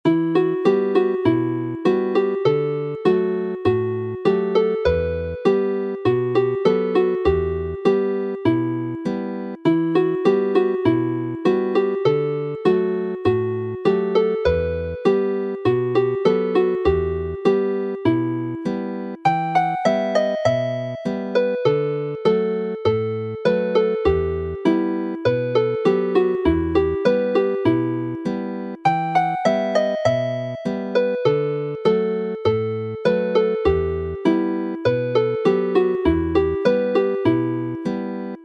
Although it is named as a jig, the music is written and played as a polka.
Chwarae'n araf
Play slowly